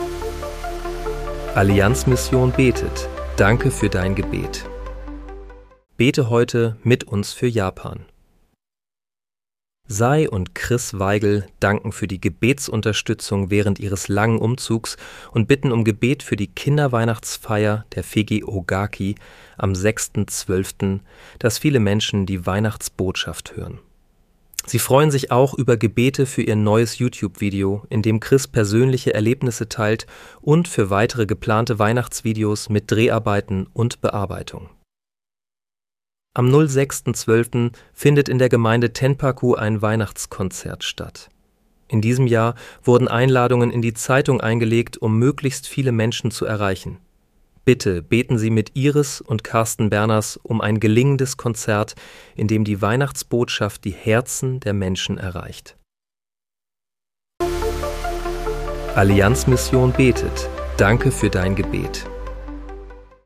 Bete am 06. Dezember 2025 mit uns für Japan. (KI-generiert mit der